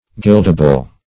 Guildable \Guild"a*ble\, a. Liable to a tax.